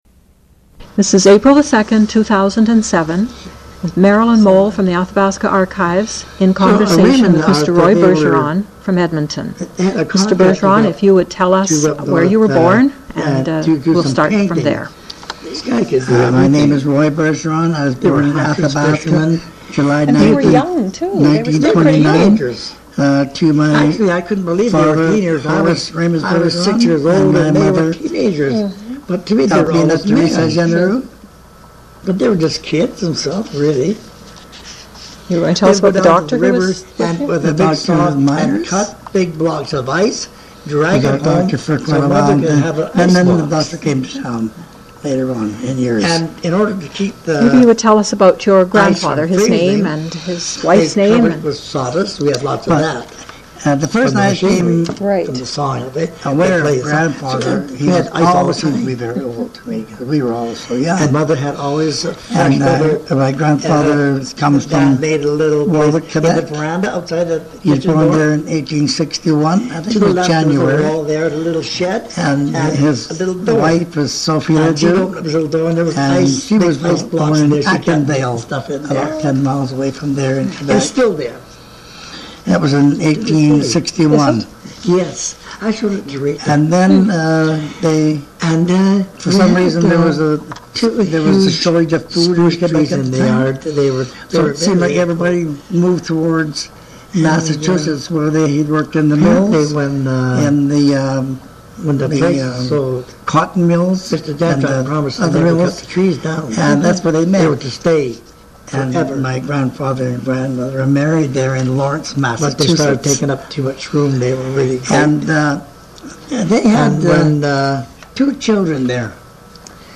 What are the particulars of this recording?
Audio - (Poor quality at beginning of second cassette for about 10 minutes).